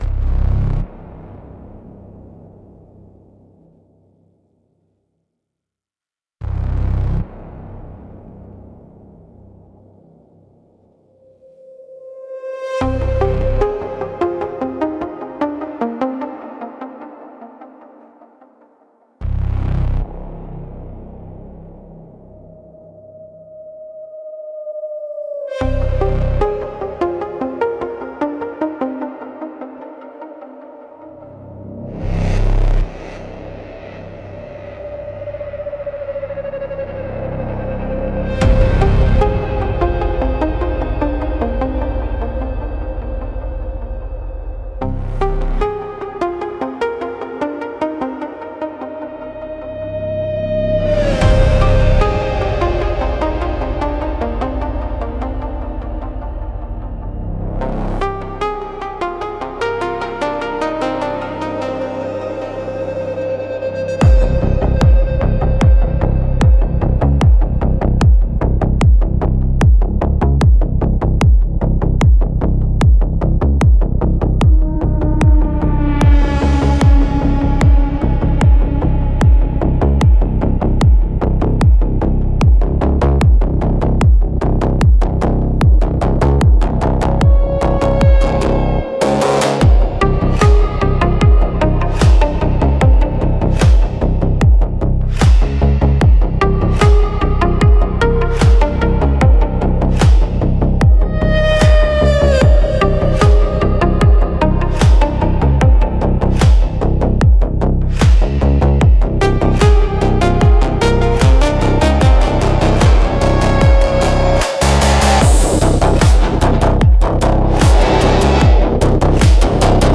Instrumentals - The Gravity of Absence